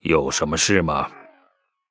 文件 文件历史 文件用途 全域文件用途 Vanjelis_tk_01.ogg （Ogg Vorbis声音文件，长度1.9秒，106 kbps，文件大小：25 KB） 源地址:地下城与勇士游戏语音 文件历史 点击某个日期/时间查看对应时刻的文件。 日期/时间 缩略图 大小 用户 备注 当前 2018年5月13日 (日) 02:57 1.9秒 （25 KB） 地下城与勇士  （ 留言 | 贡献 ） 分类:范哲利斯 分类:地下城与勇士 源地址:地下城与勇士游戏语音 您不可以覆盖此文件。